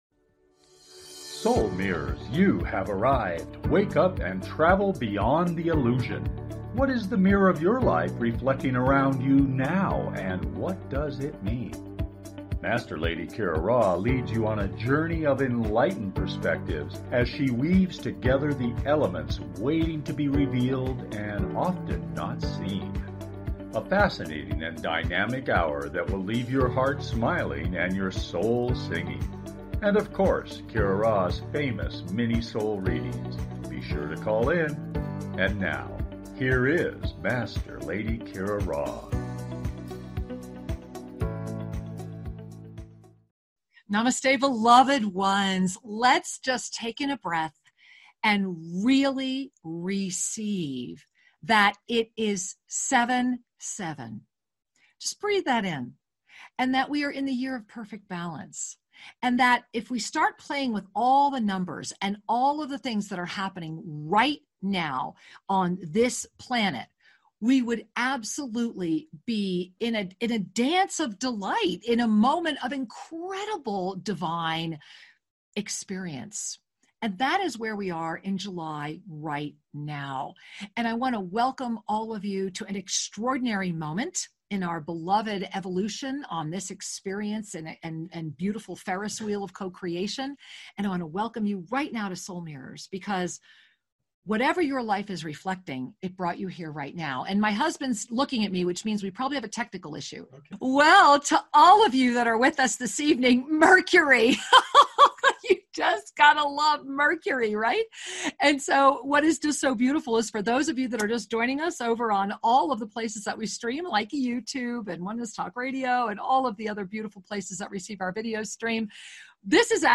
Please consider subscribing to this talk show.
Lively, entertaining, and refreshingly authentic, the hour goes quickly! Extraordinary guests, from the cutting edge, add depth & dimension to the fascinating conversation.